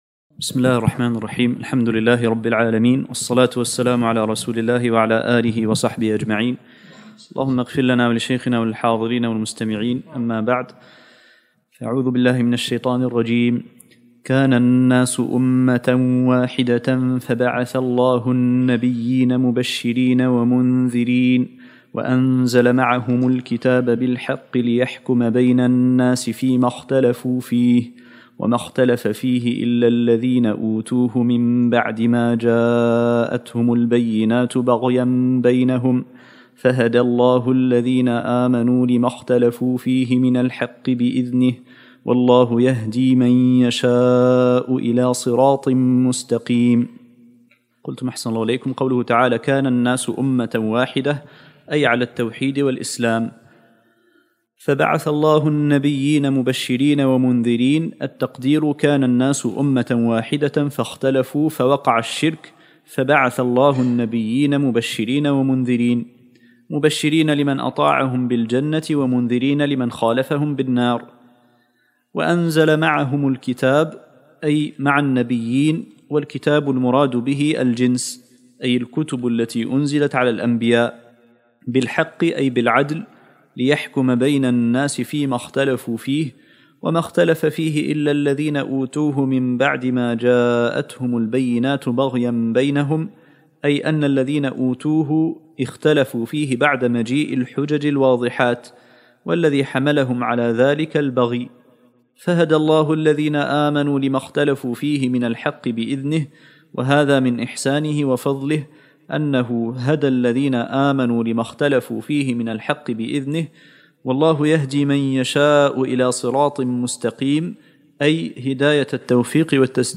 الدرس الخامس عشرمن سورة البقرة